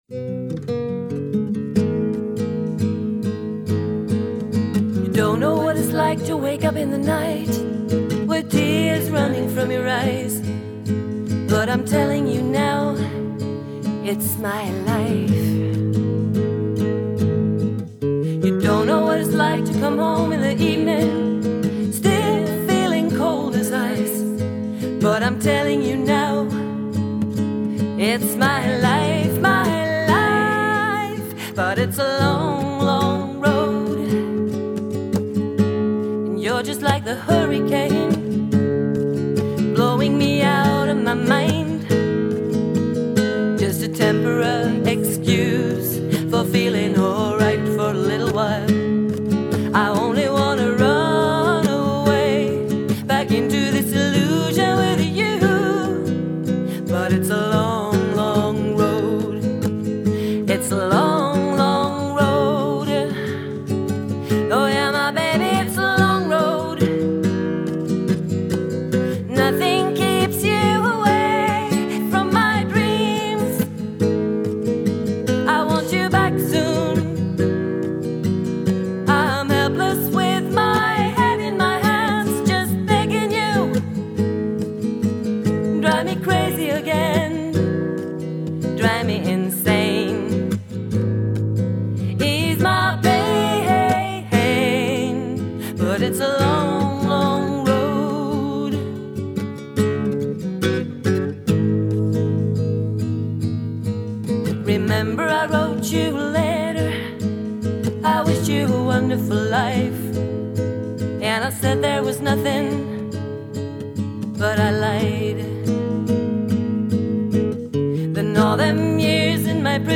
Rösten är svårbedömd på denna låt då det väll är två körtjejer som sjunger samtidigt + att det är endel komprimering som gör rösten väldigt essig.
Nope! endast en tjej, och låten är enligt henne själv inte en ripp av peerl jam.
Hon körar sig själv då eller hör jag i syne?
Hon har även gjort musiken.
Som sagt, en lite större instrumentering vore riktigt trevligt!
Jag tycker att låten är bra och att hon sjunger bra, även bra andra stämma.
Gitarren kunde nog låta mindre monotont, lite mer varierat, gärna lite glesare sas, som det är nu så är det lite som ljudmatta (gitarren alltså).
* dvs. struntar i teori bakom vad som är en takt... hamnade på 3&6/8 :lol: